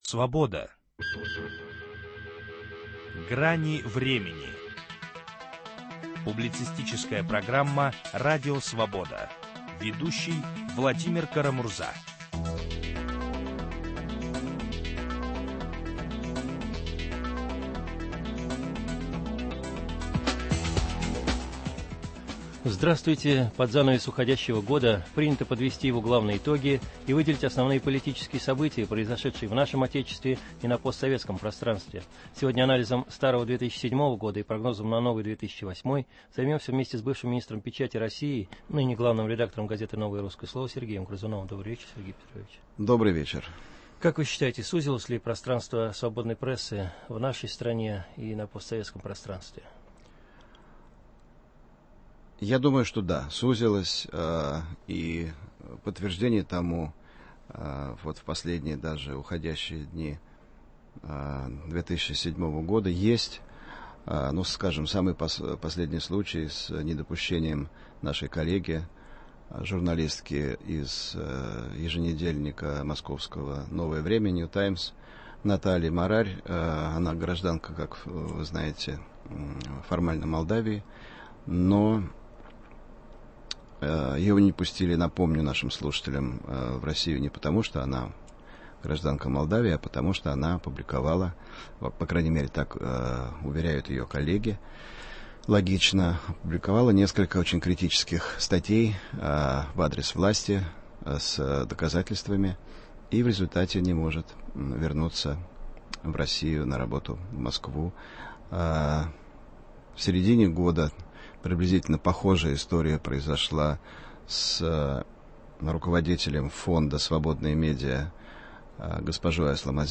О главных событиях уходящего года в России и на постсоветском пространстве беседуем с бывшим министром печати, главным редактором газеты "Новое русское слово" Сергеем Грызуновым.